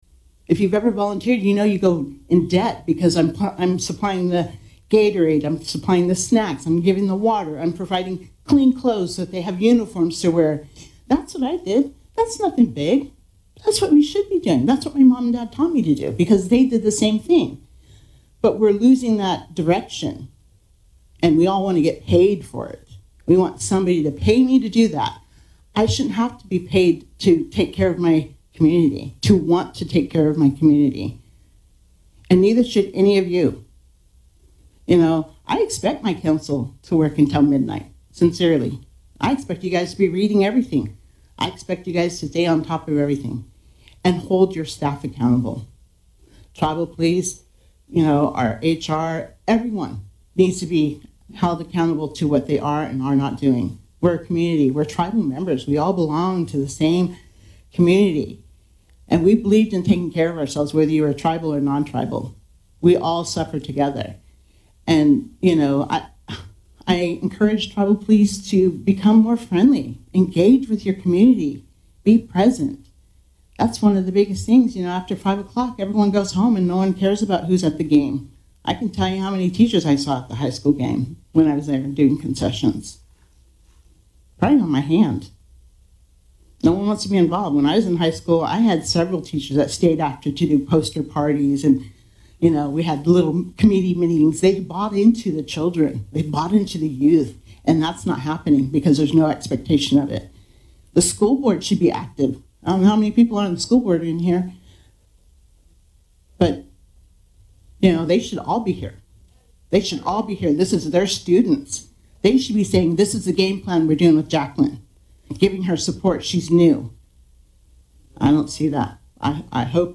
This audio recording is the third hour of 3 hours of the Hoopa Valley Tribal Council Emergency Meeting held at the Tribal Council Chambers on Wedsnesday, March 11, 2026. This audio has been slightly edited to delete the audio of the recorded steps heard as people walked to the microphone, or the sound of microphone adjustments, or any statements made far enough away that raising the playback volume could not clearly be understood.